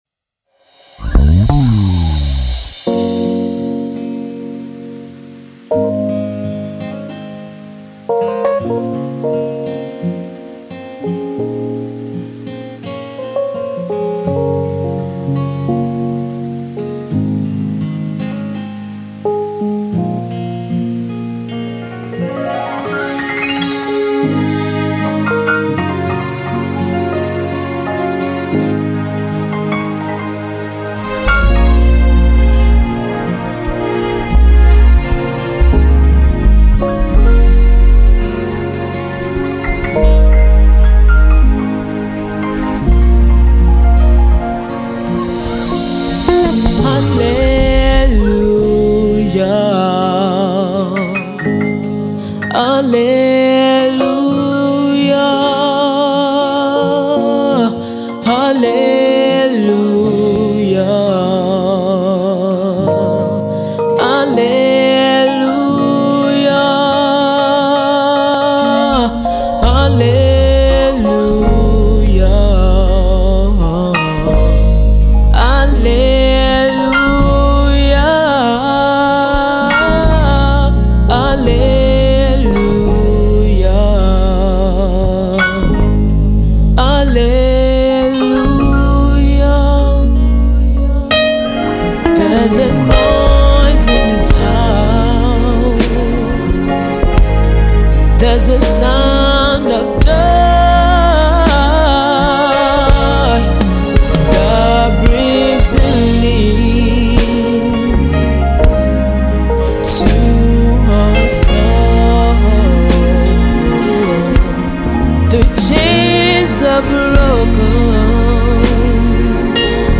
gospel singer
gospel rock